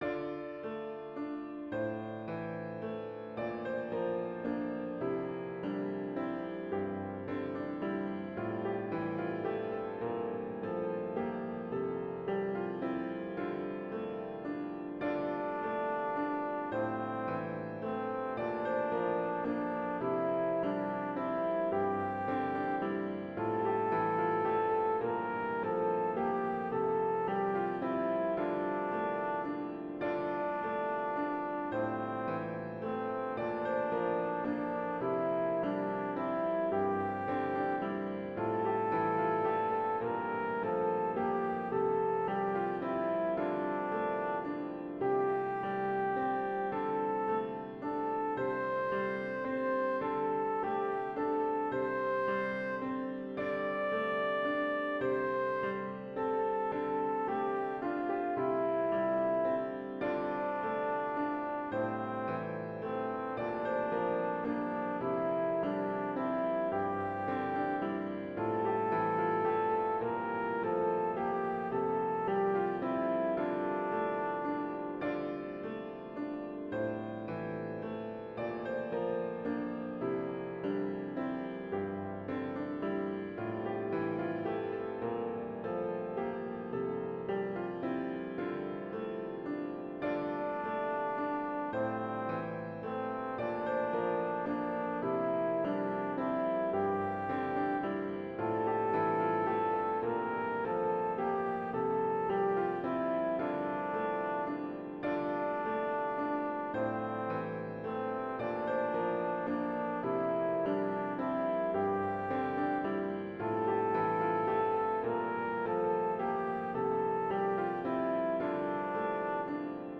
Voicing/Instrumentation: Choir Unison
Piano